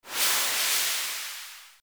/ F｜演出・アニメ・心理 / F-30 ｜Magic 魔法・特殊効果
ッシャーシャー